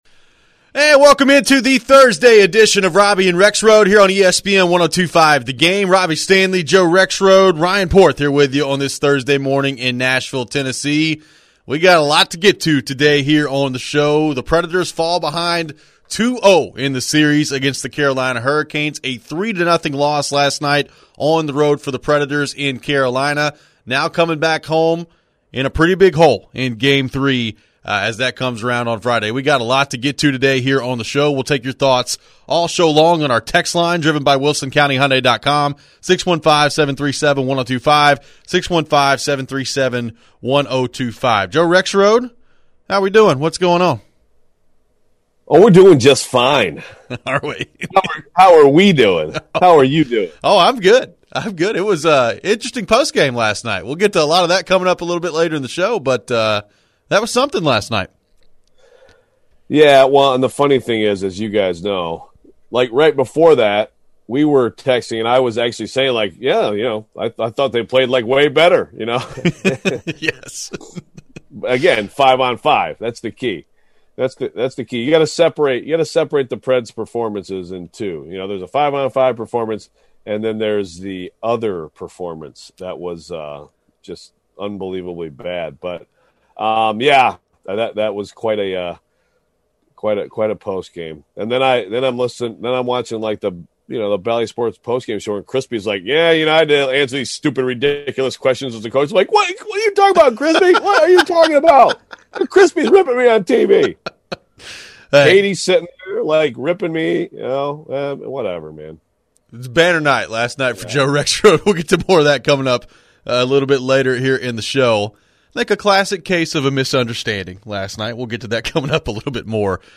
Plus, the guys take texts and calls with more reaction from Preds fans.